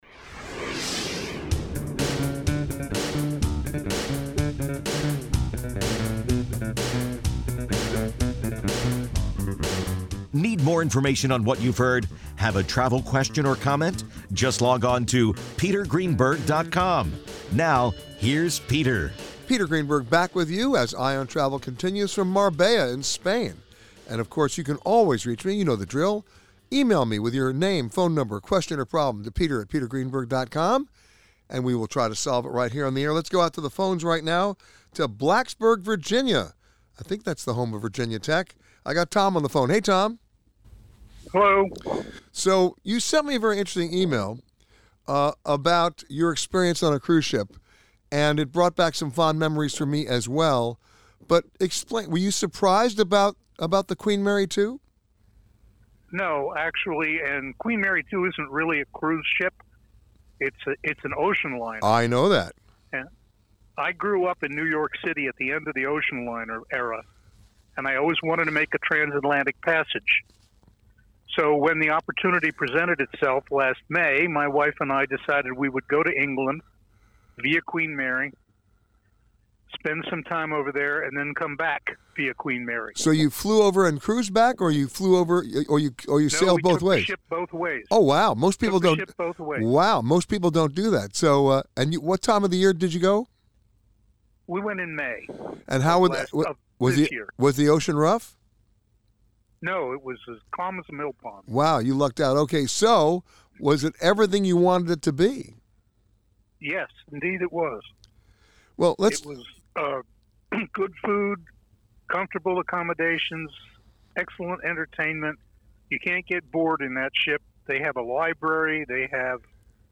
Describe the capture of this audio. This week’s broadcast of Eye on Travel is from the Puente Romano Beach Resort in Marbella, Spain, and the global summit of the Leading Hotels of the World.